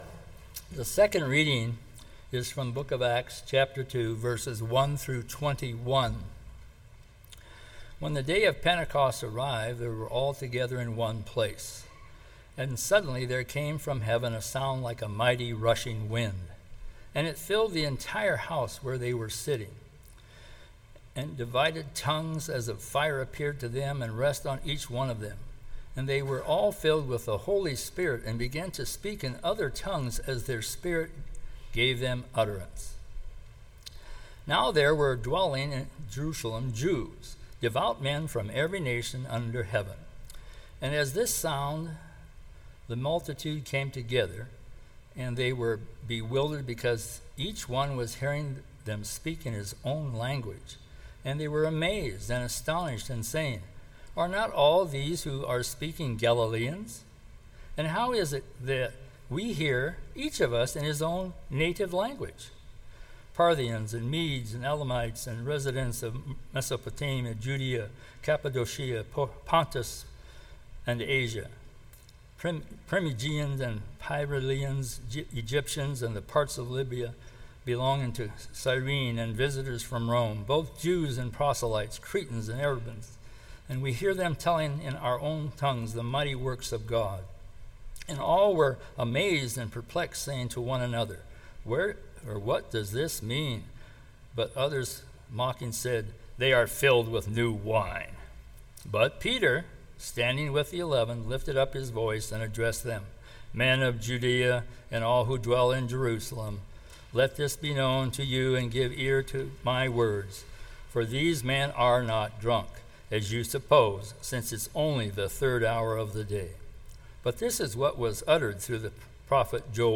This sermon meditates on what that means.